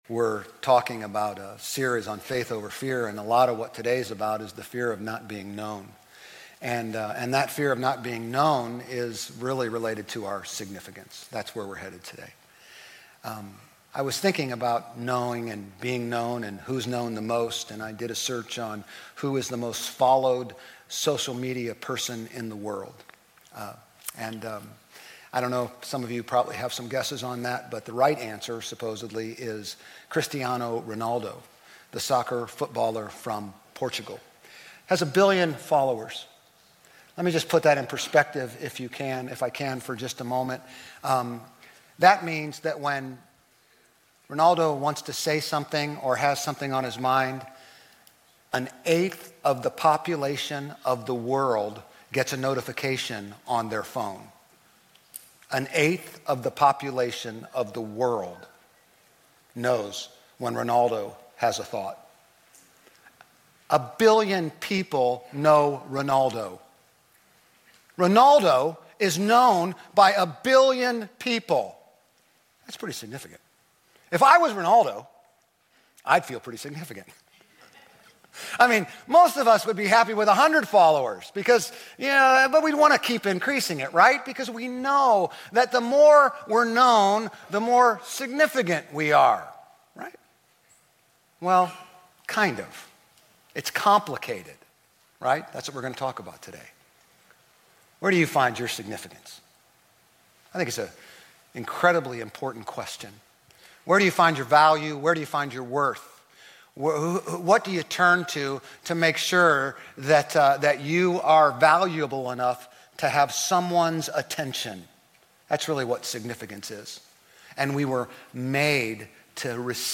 Grace Community Church Old Jacksonville Campus Sermons 6_8 Old Jacksonville Campus Jun 08 2025 | 00:32:07 1x 00:00 / 00:32:07 Subscribe Share RSS Feed Share Link Embed